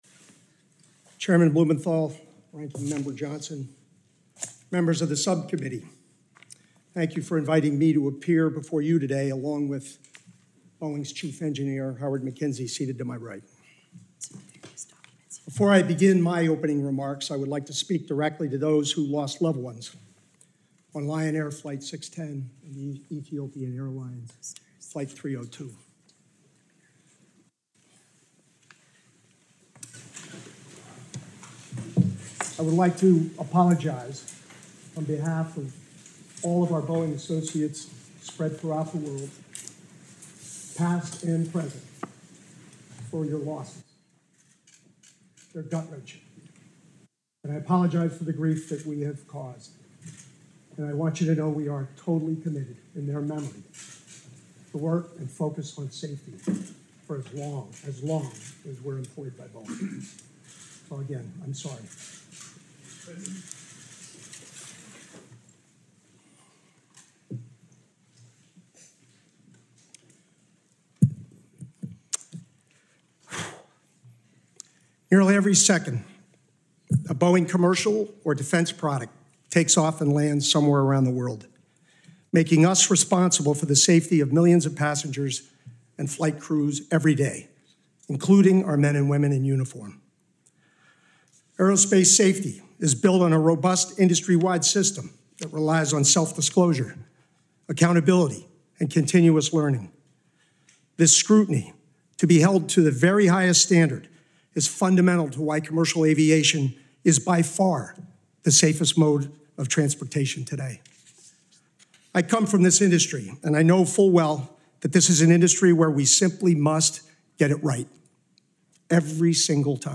Opening Statement on Boeing Safety Culture Before the Senate Permanent Subcommittee on Investigations
delivered 18 June 2024, U.S. Capitol Building, Washington, D.C.
Audio Note: Female voice whispering near the beginning present at source audio. AR-XE = American Rhetoric Extreme Enhancement